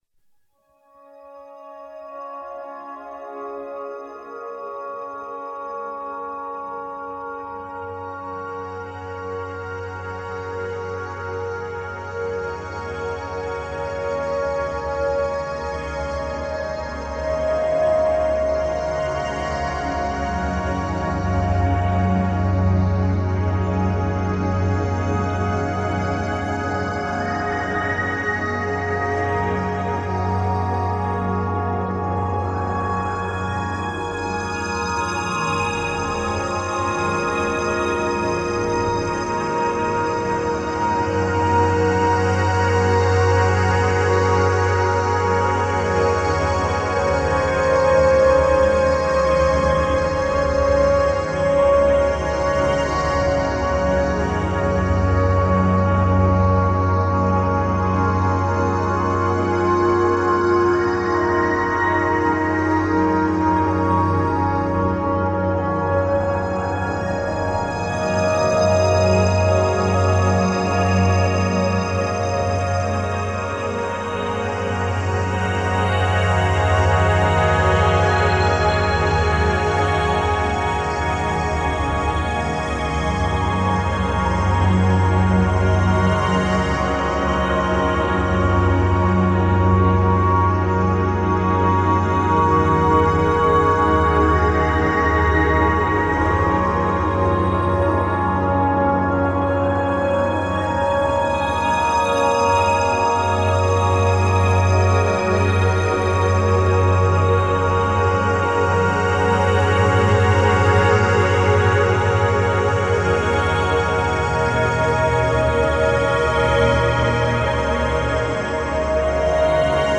live to tape solo loop works
live loop tracks